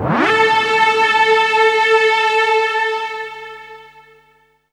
strTTE65021string-A.wav